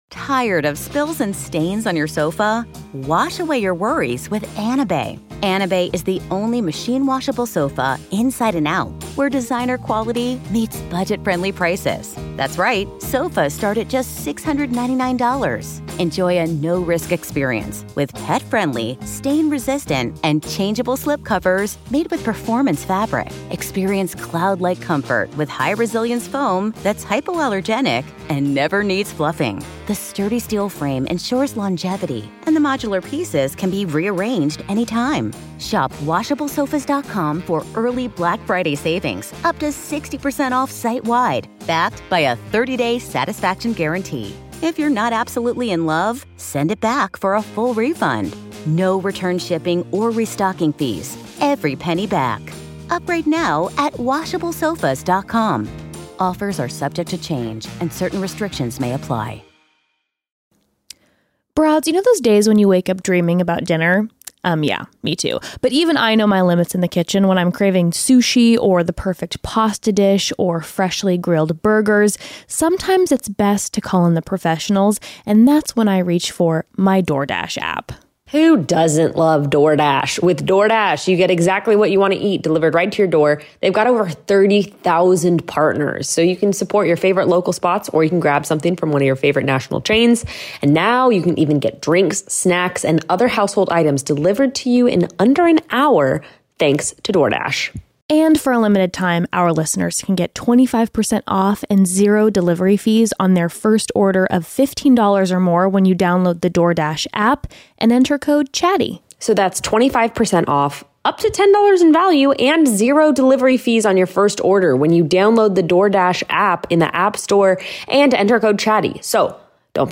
First The Broads chat about their 2022 resolutions!